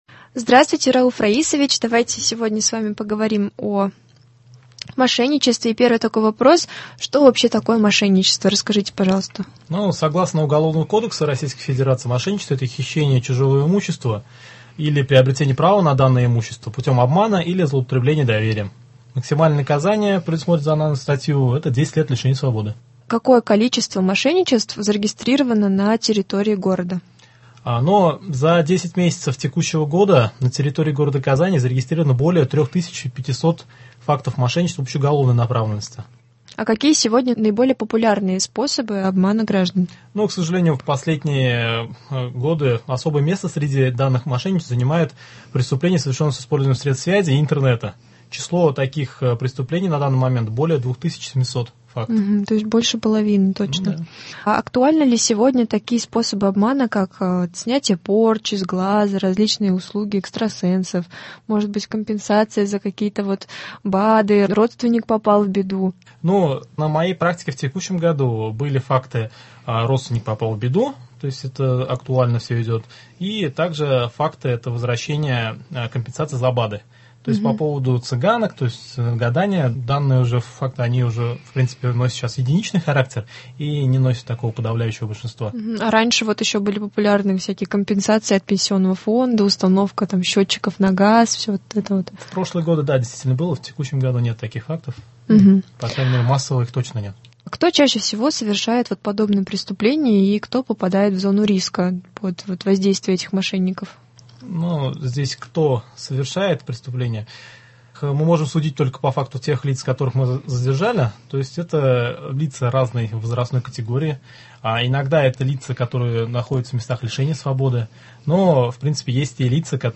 О борьбе с мошенничеством рассказал у нас в студии